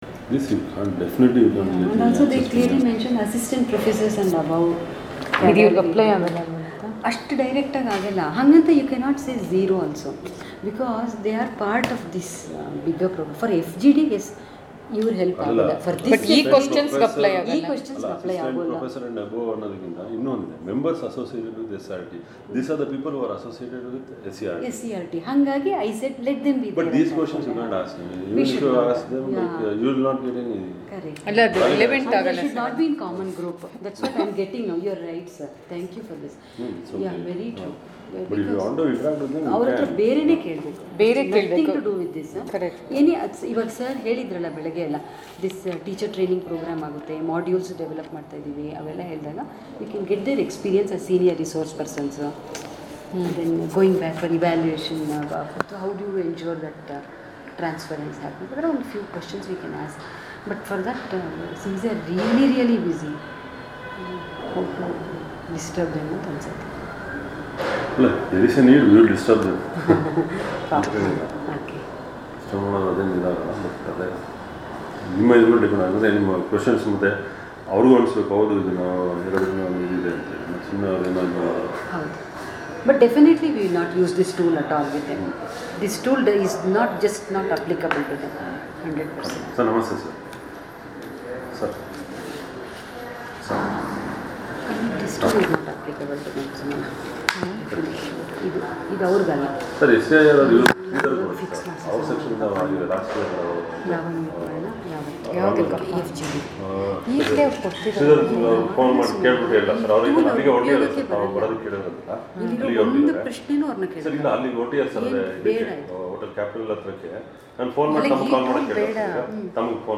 6.1.6 ( B ) CSSTE_KA_Interview Audio Recordings - CKAN